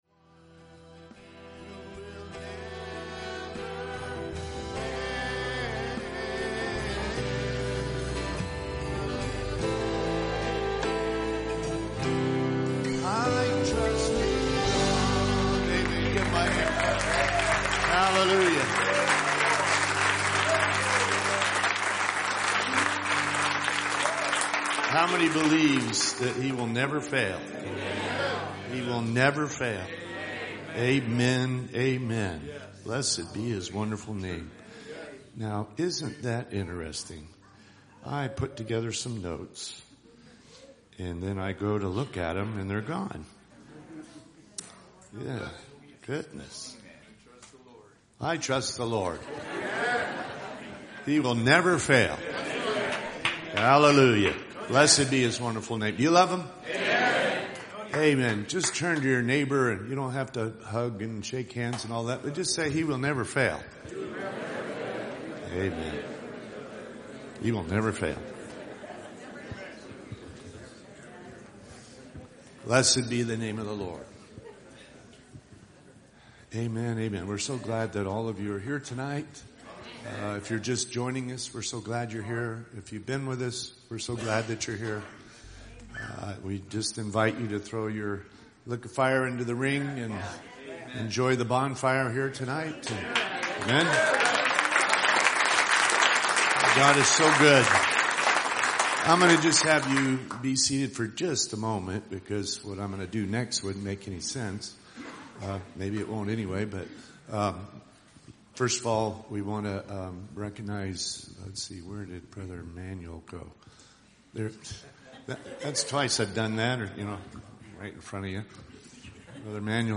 Series: 2026 Southern Arizona Fellowship Meetings